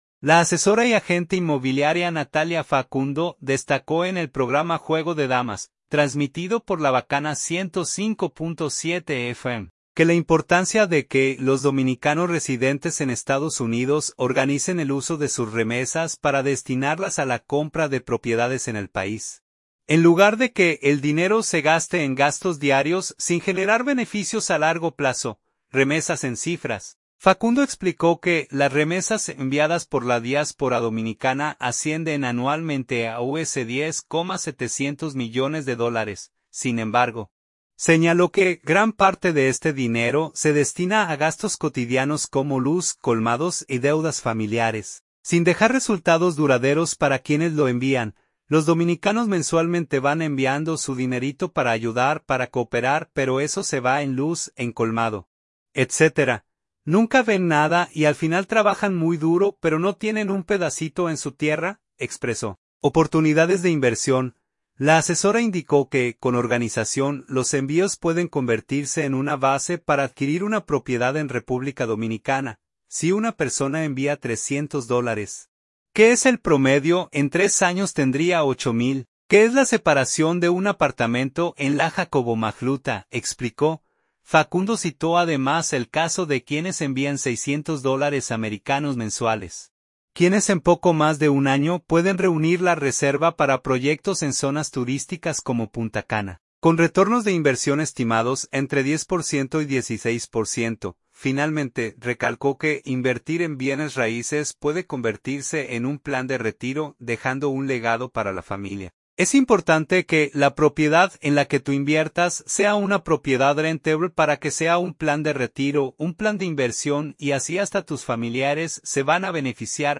Juego de Damas, transmitido por La Bakana 105.7 FM